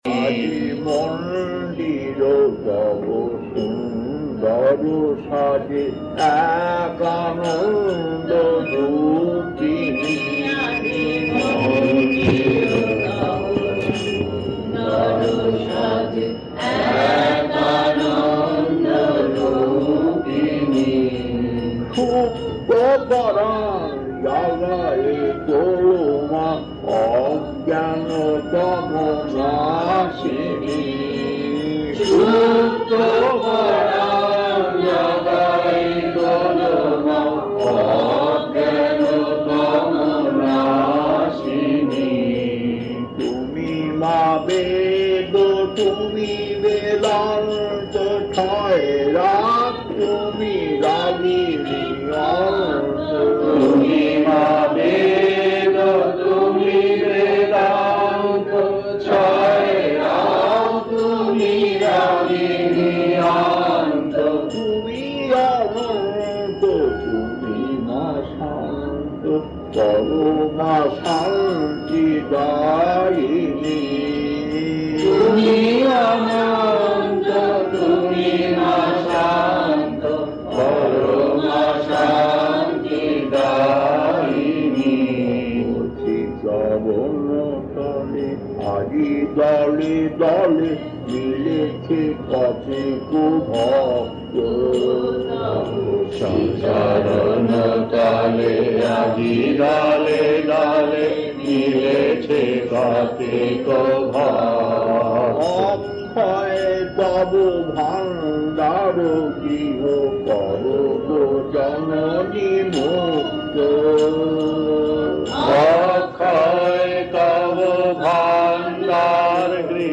Kirtan A3-2 Gold Croft 1994 1.